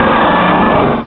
Cri de Laggron dans Pokémon Rubis et Saphir.